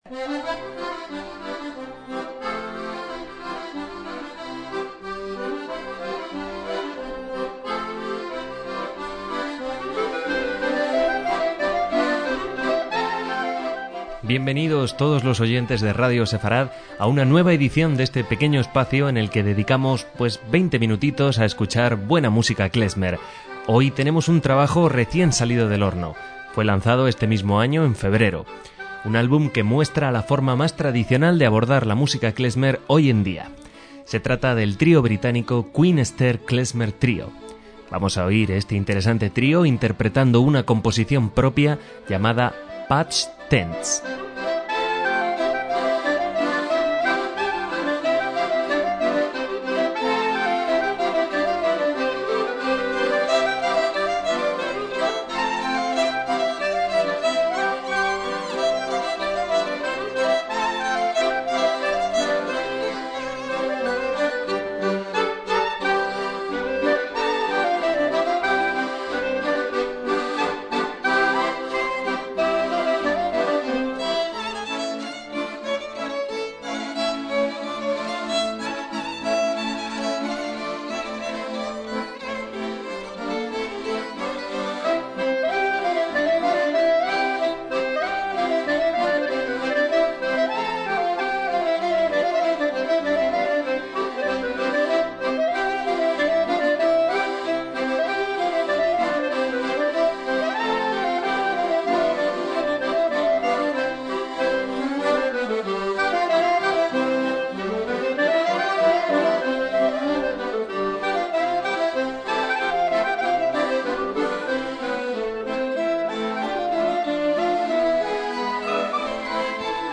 MÚSICA KLEZMER
clarinete, flautas dulces
violín
tsimbl